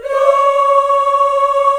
AFROLA C#5-L.wav